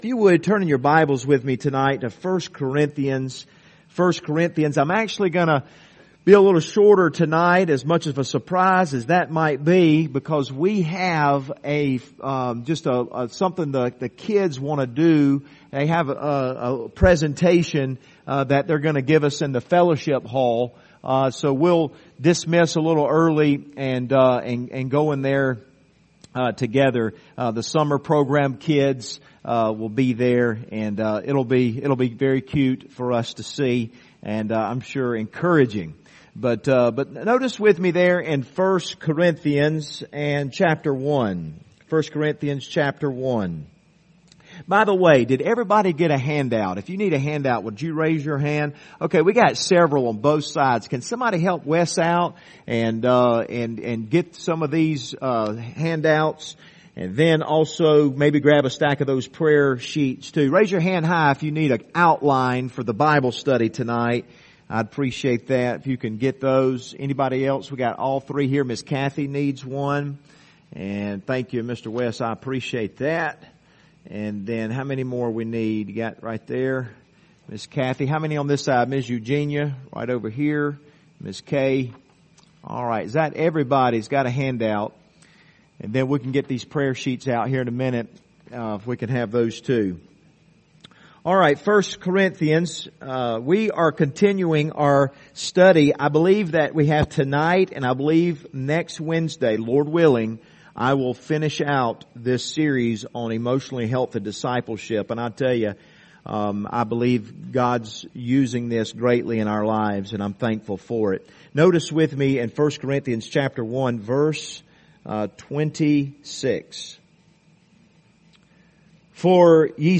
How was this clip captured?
Passage: 1 Corinthians 1:26-29 Service Type: Wednesday Evening